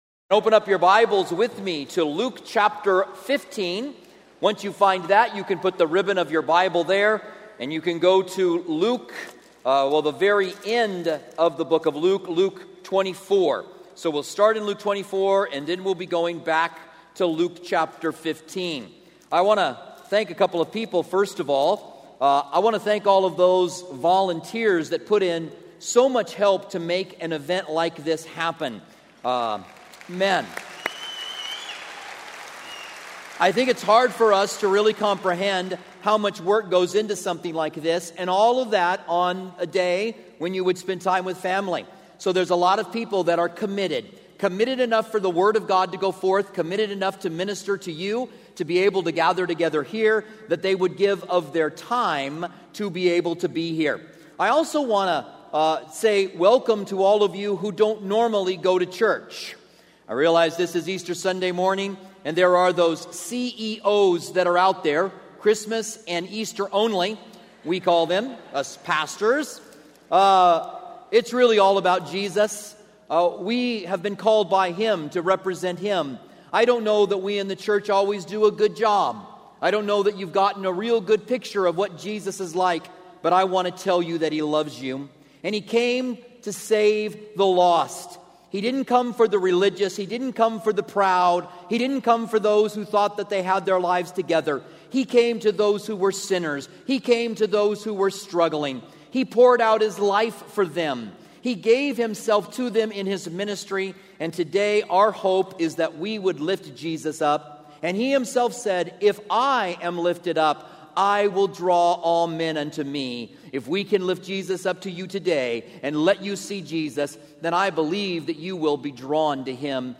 Holiday Message